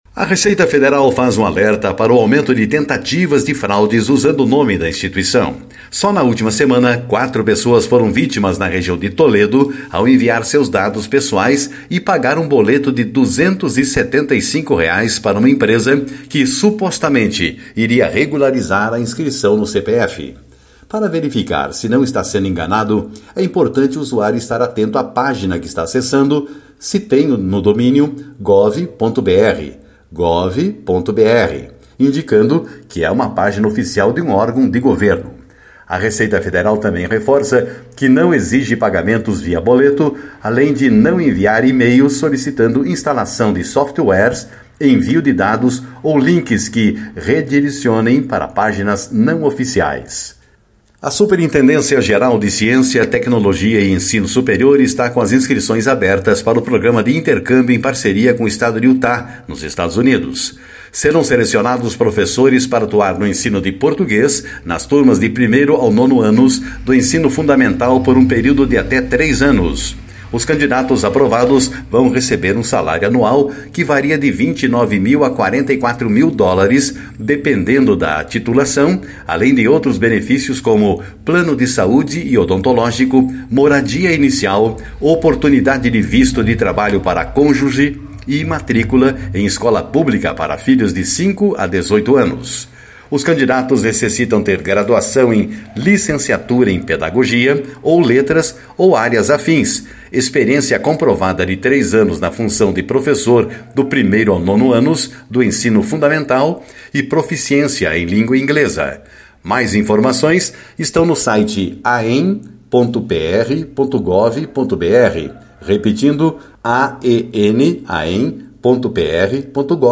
Por Jornalismo.